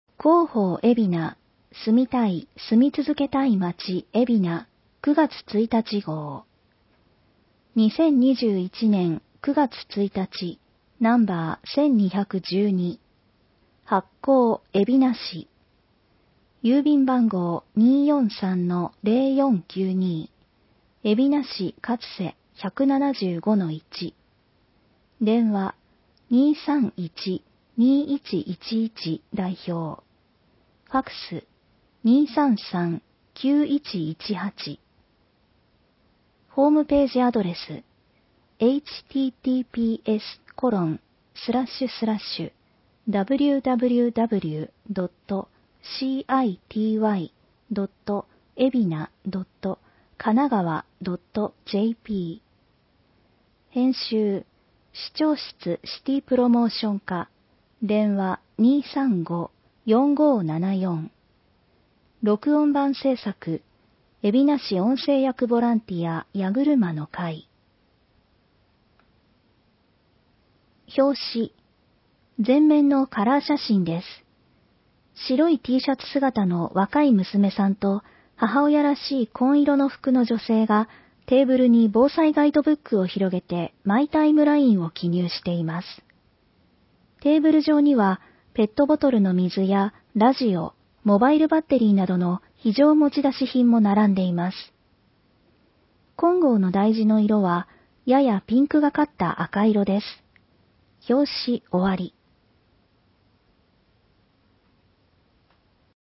広報えびな 令和3年9月1日号（電子ブック） （外部リンク） PDF・音声版 ※音声版は、音声訳ボランティア「矢ぐるまの会」の協力により、同会が視覚障がい者の方のために作成したものを登載しています。